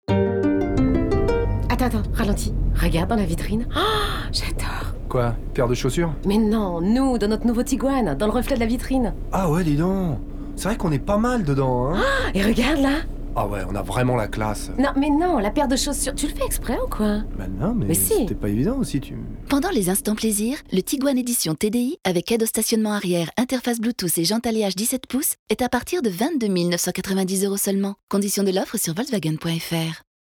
Outre cette pub TV, la campagne repose sur deux spots radios.